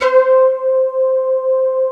74 SYN FLT-L.wav